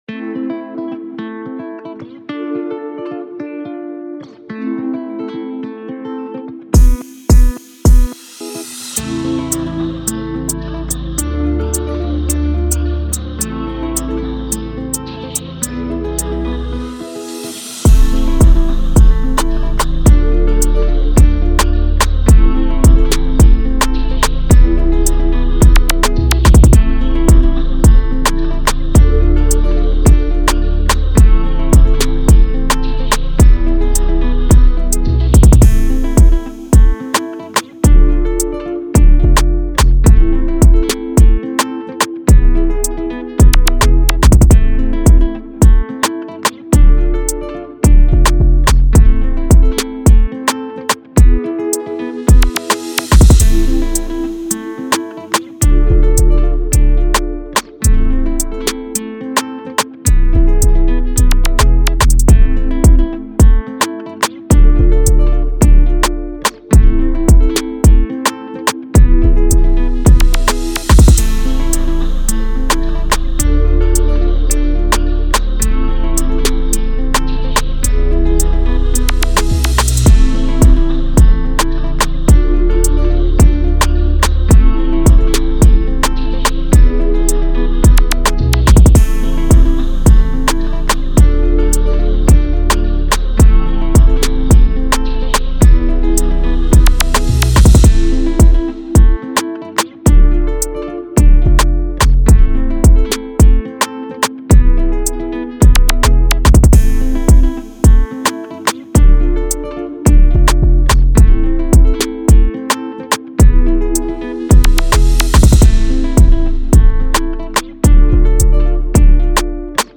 Reggae Instrumentals